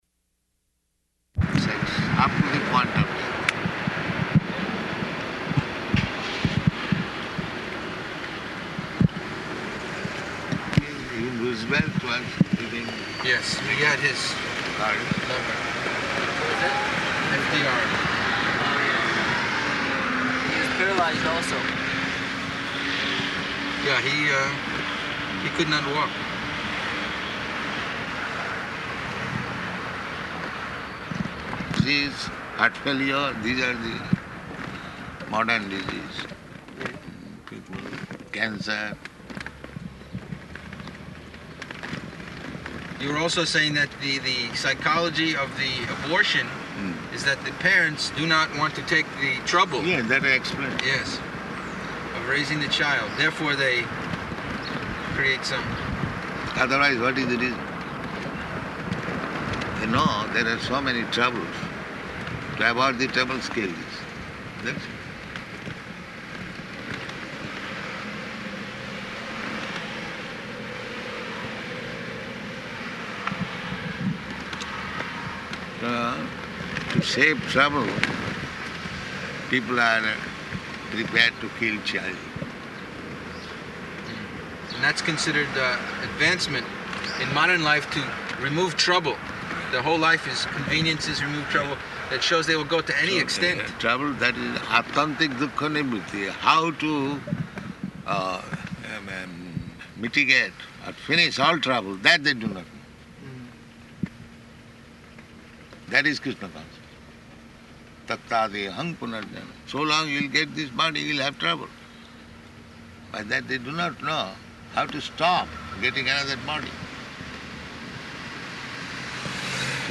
Morning Walk --:-- --:-- Type: Walk Dated: July 9th 1975 Location: Chicago Audio file: 750709MW.CHI.mp3 [in car] Prabhupāda: ...sex, up to the point of death.